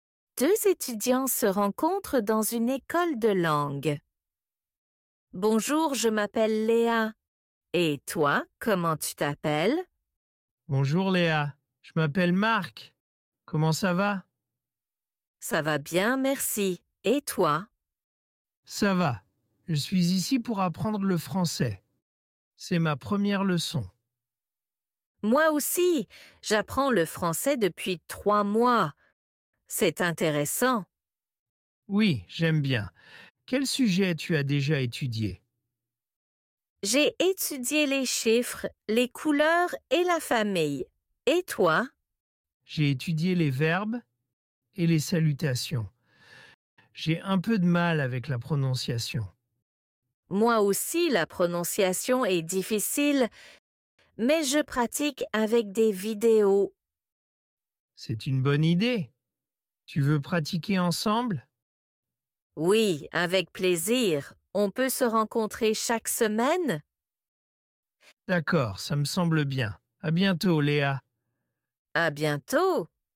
Deux étudiants se rencontrent A1
Dialogue FLE
Deux-etudiants-se-rencontrent-dans-une-ecole-de-langue.mp3